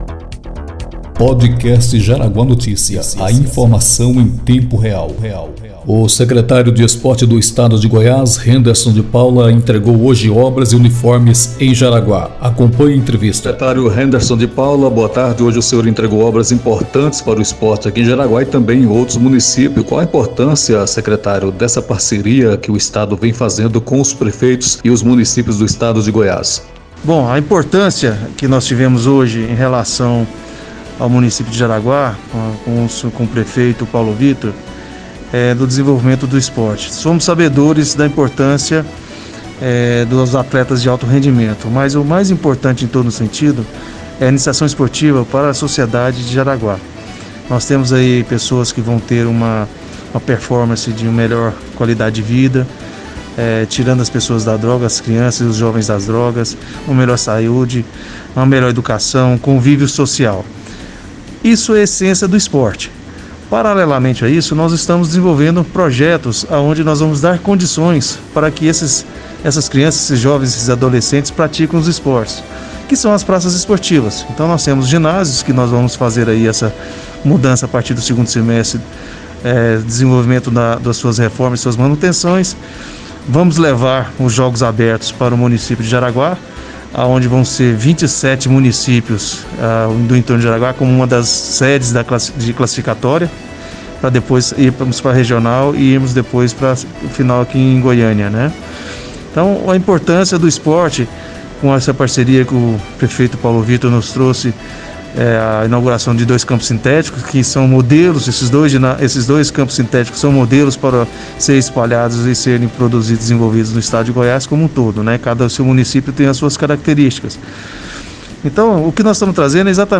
Podcast com o secretário Henderson de Paula